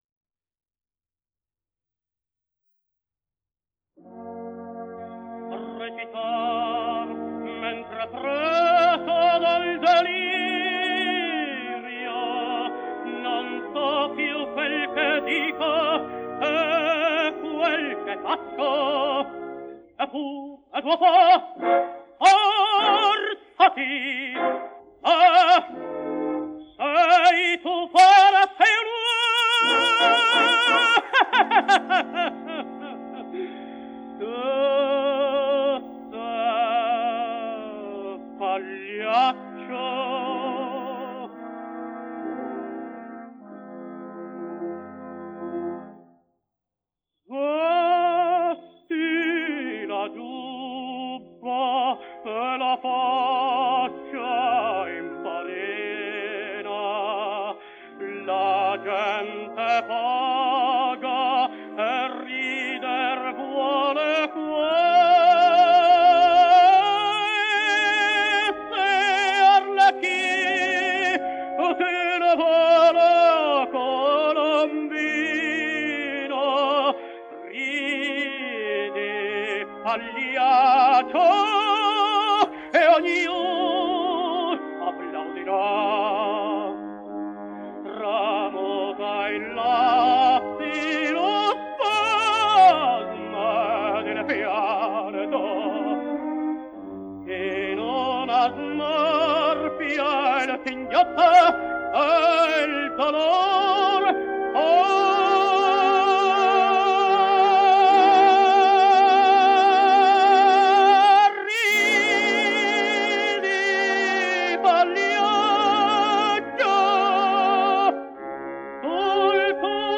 denoised.wav